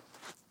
Sand Foot Step 3.wav